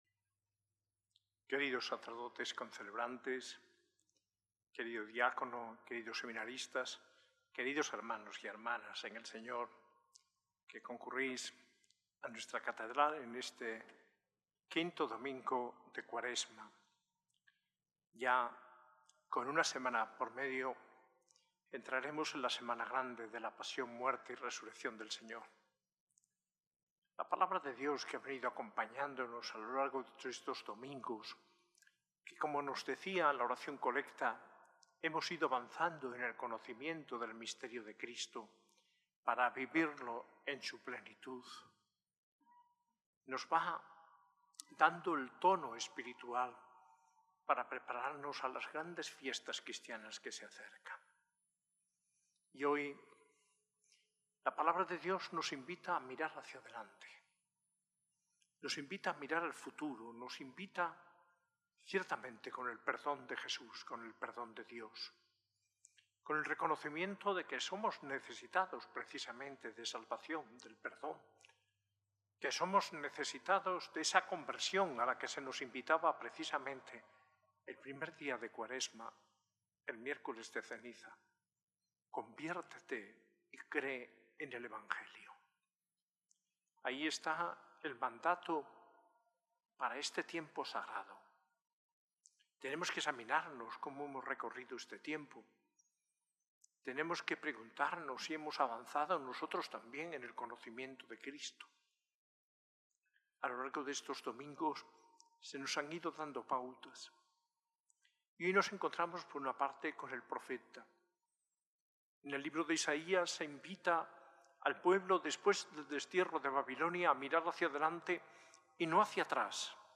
Homilía del arzobispo de Granada, Mons. José María Gil Tamayo, en el IV Domingo de Cuaresma, en la S.A.I Catedral el 6 de abril de 2025.